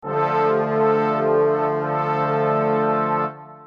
标签： 电影 喇叭 以来 请求 长号
声道立体声